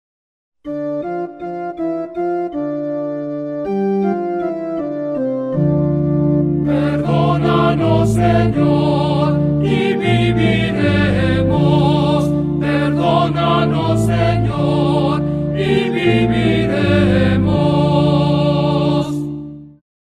SALMO RESPONSORIAL Del salmo 129 R. Perdónanos, Señor, y viviremos.